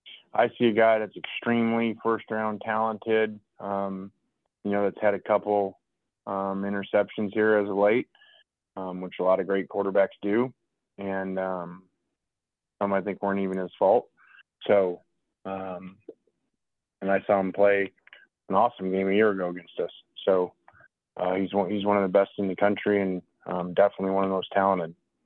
Ole Miss coach Lane Kiffin discusses his thoughts on the Georgia quarterback: